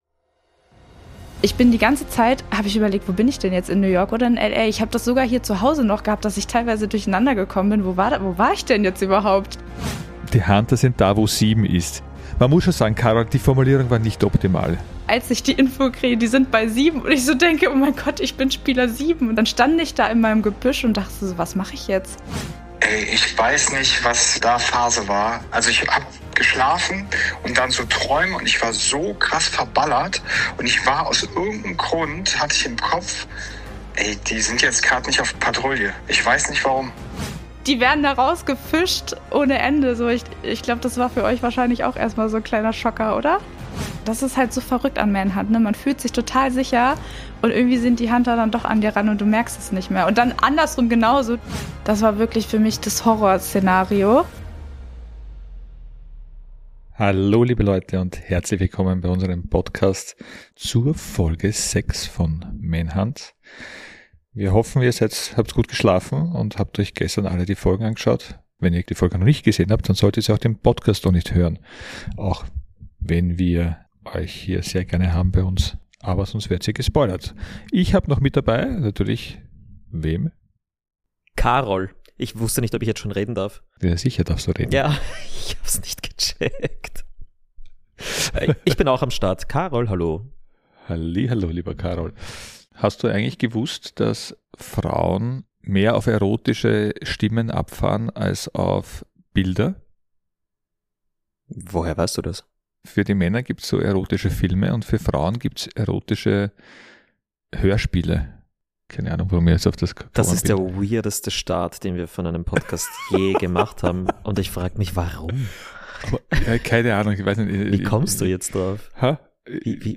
zumindest kurz am Telefon.